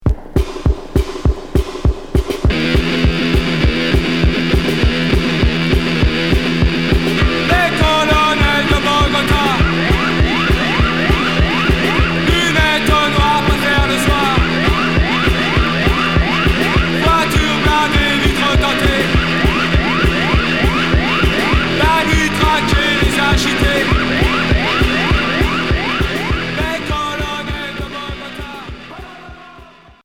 Punk industriel Deuxième Maxi 45t